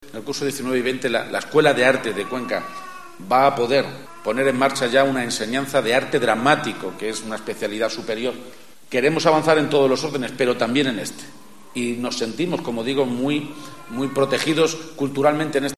Así lo ha anunciado Emiliano García Page durante la entrega de los premios ‘Silla de Oro 2018’ de ASPAYM Cuenca